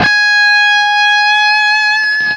LEAD G#4 CUT.wav